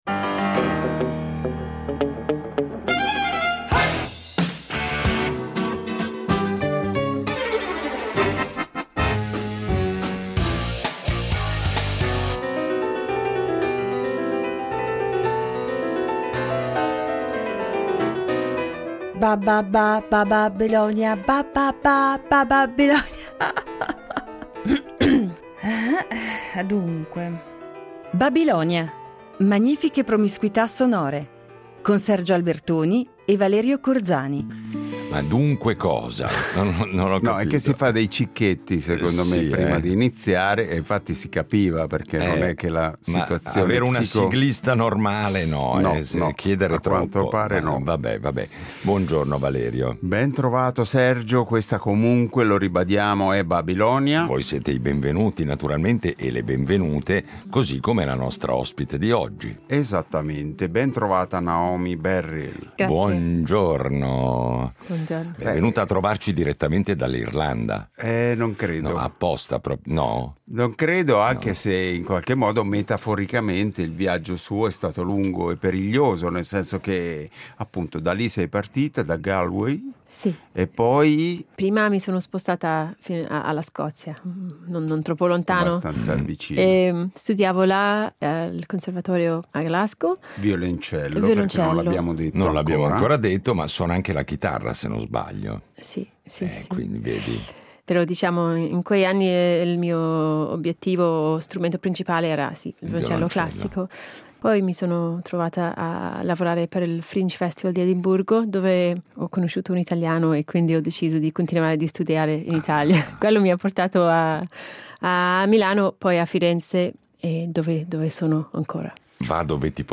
Oggi abbiamo il piacere di ospitare sulla nostra arca radiofonica una violoncellista, chitarrista e cantautrice irlandese che da molti anni, ormai, vive a Firenze ed è quindi attiva in Italia.